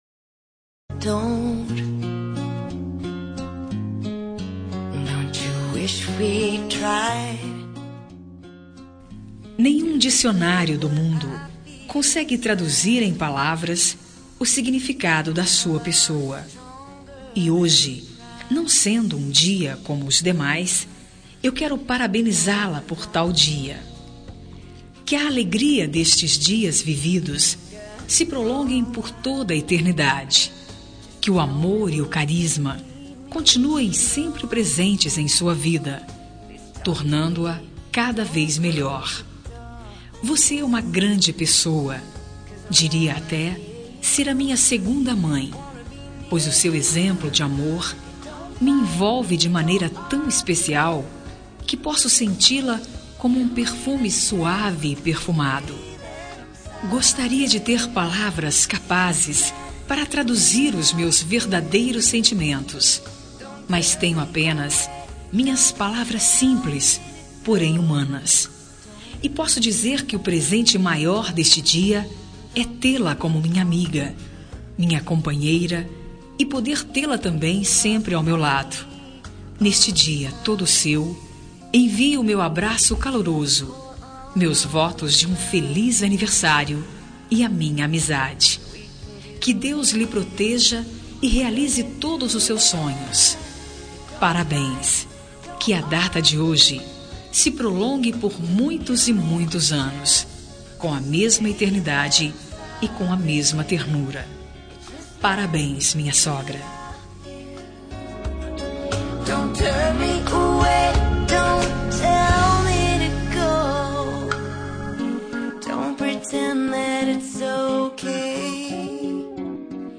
Telemensagem de Aniversário de Sogra – Voz feminina – Cód: 1968